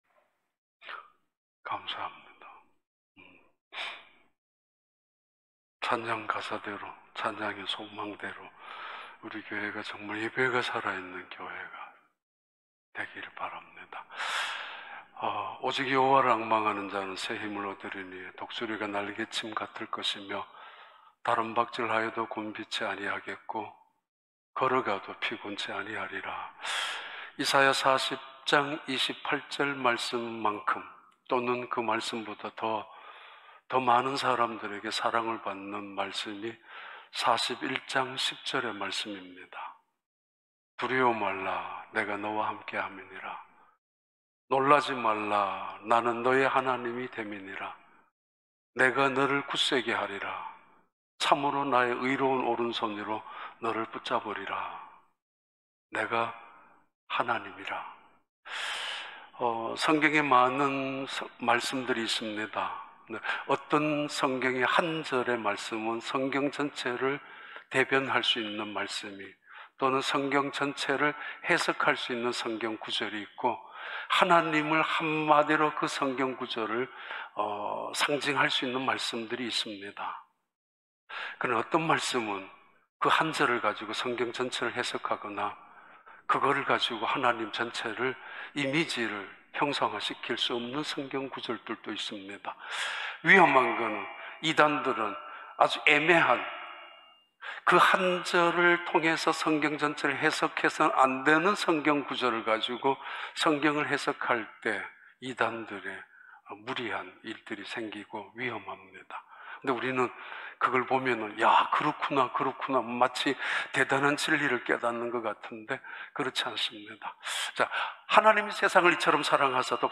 2021년 7월 11일 주일 4부 예배